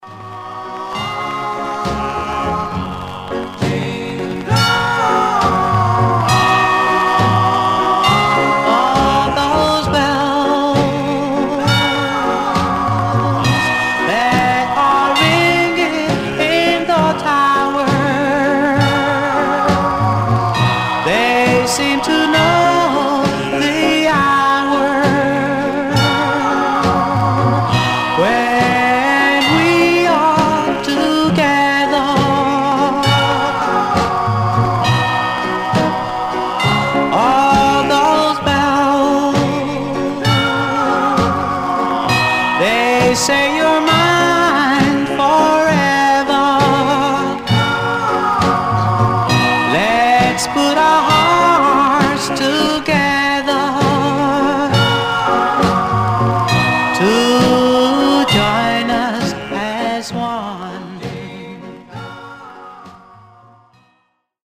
Surface noise/wear
Mono